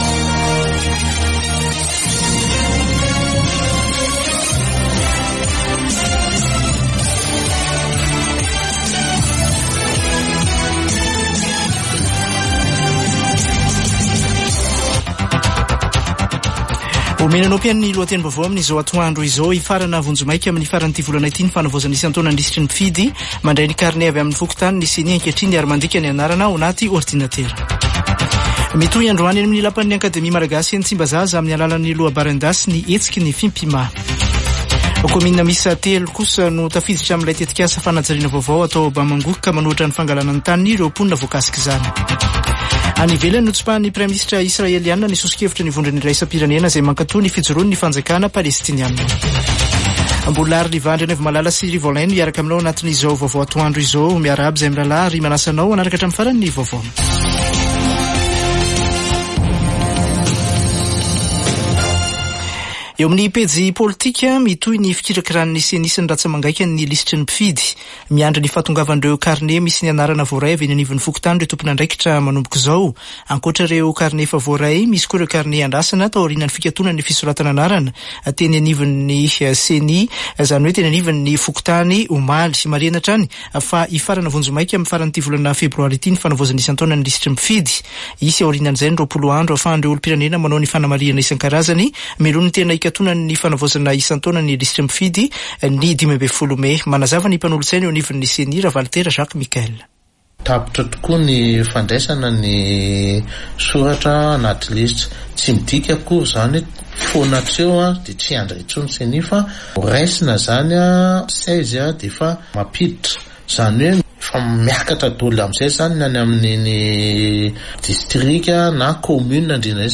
[Vaovao antoandro] Zoma 16 febroary 2024